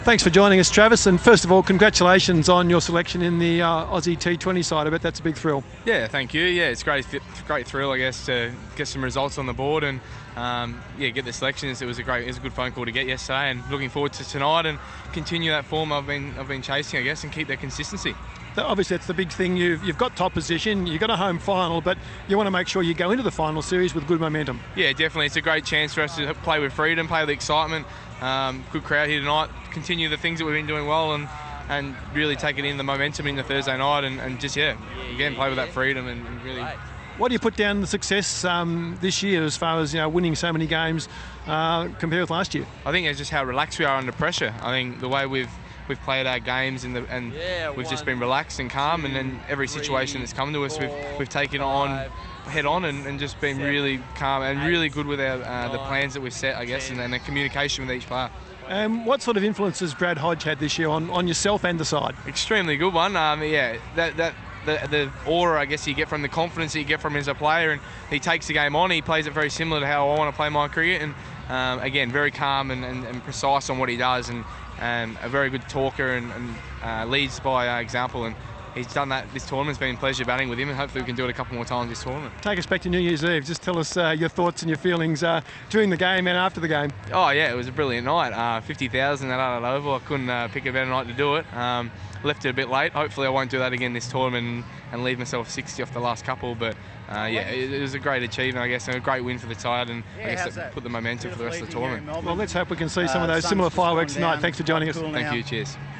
INTERVIEW: Travis Head talks about his inclusion in the Australian T20 squad.